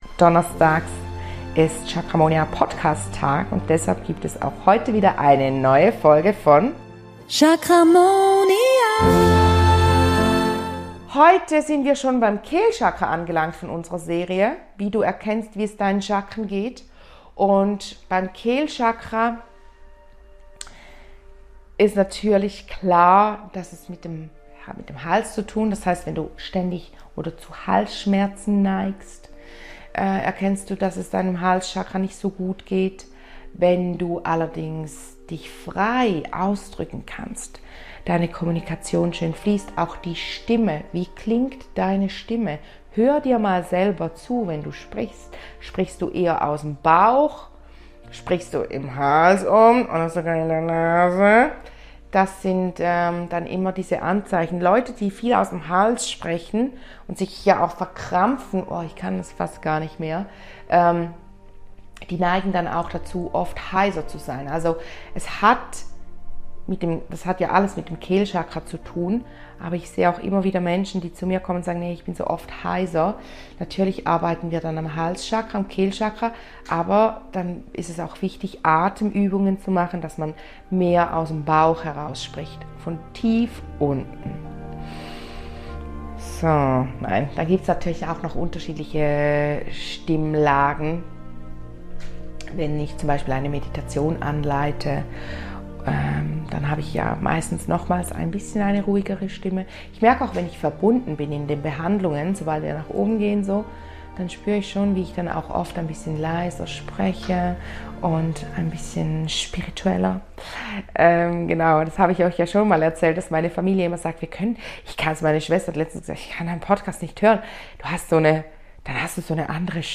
Gemeinsam werden wir chanten, um deine Verbindung zu deinem Kehlchakra zu vertiefen.